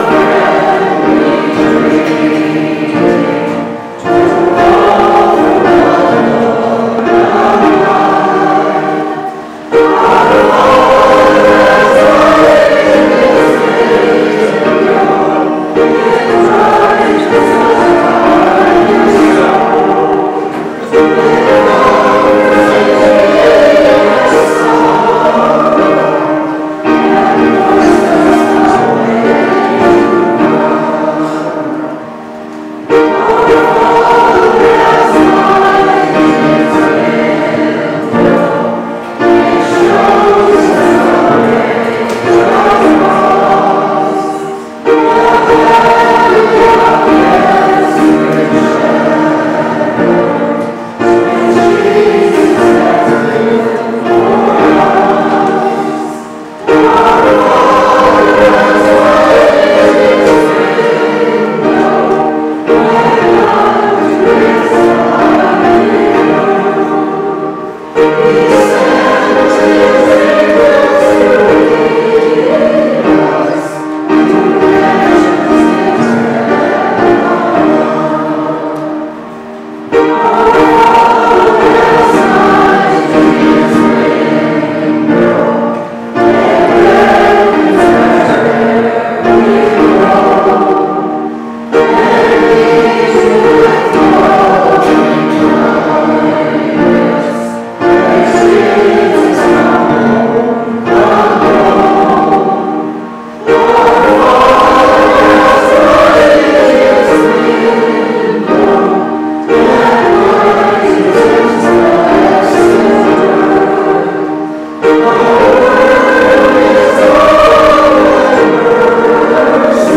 Singing at Danebod Folk Meeting